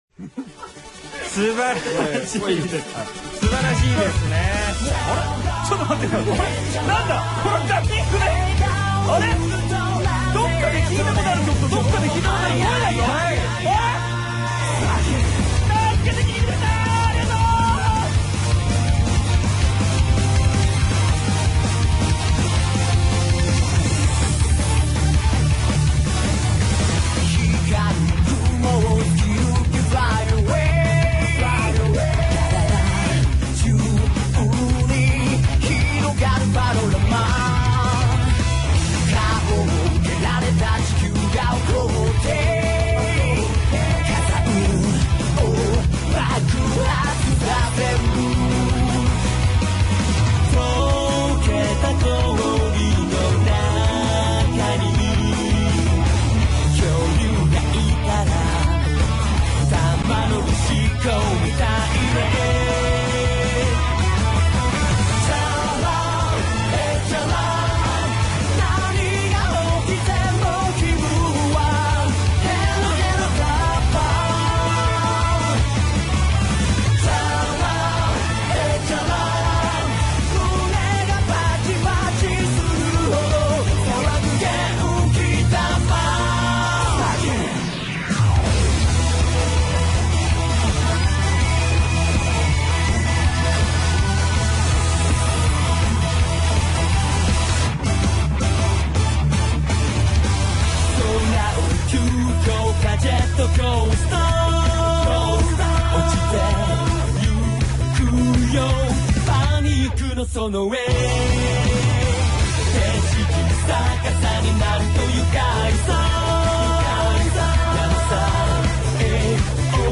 la canción es un cover
j-rock